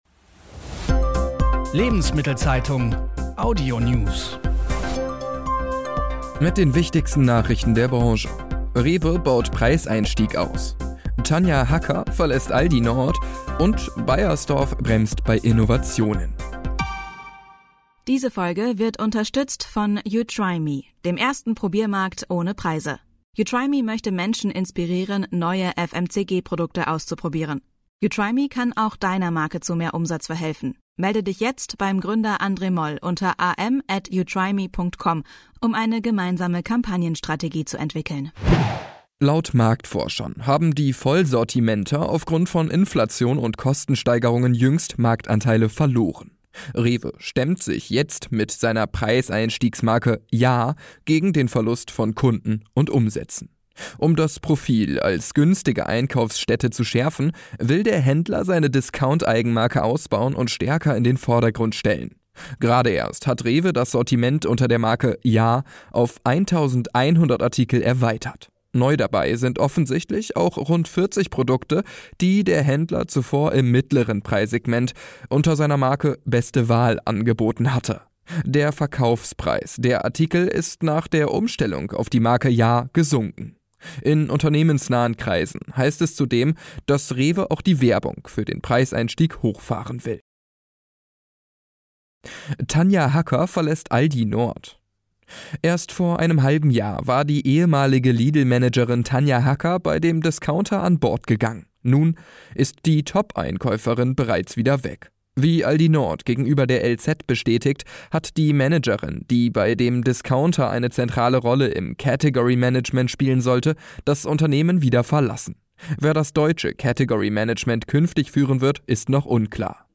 Wirtschaft , Nachrichten